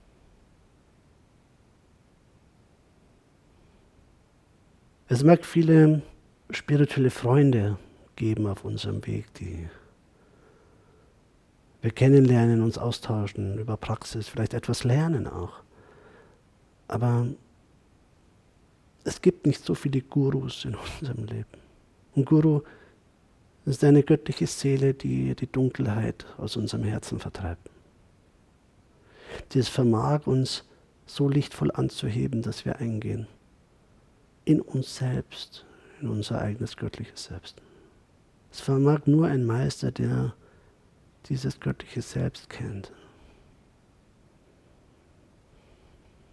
Meditation Live-Aufnahme